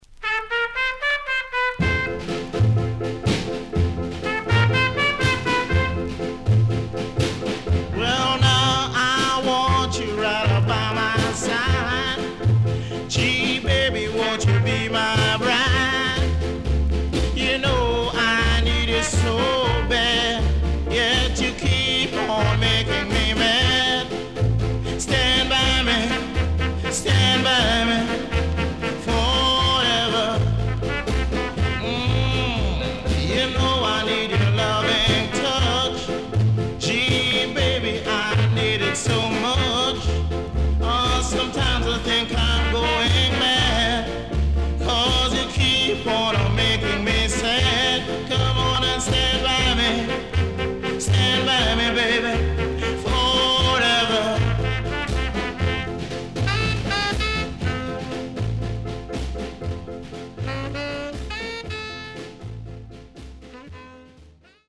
CONDITION SIDE A:VG(OK)〜VG+
SIDE A:所々チリノイズ入ります。